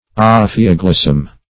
Ophioglossum synonyms, pronunciation, spelling and more from Free Dictionary.
ophioglossum.mp3